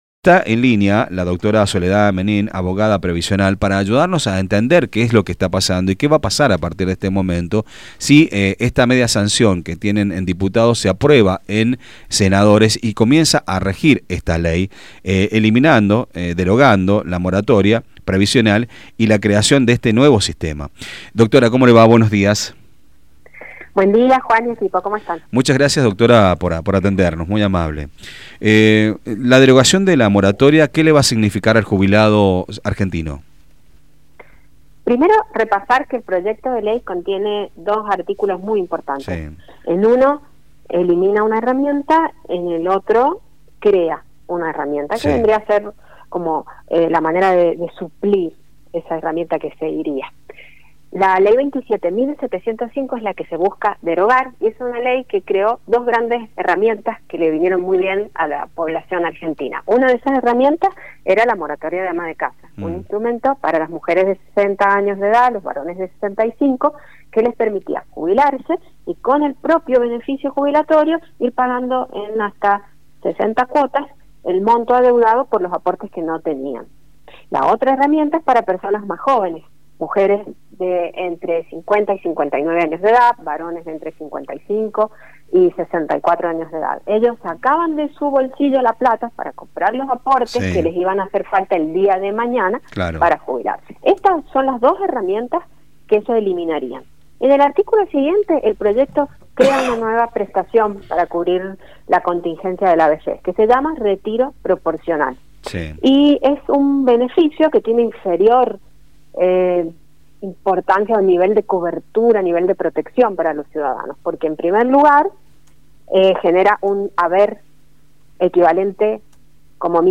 En entrevista con Radio Sarmiento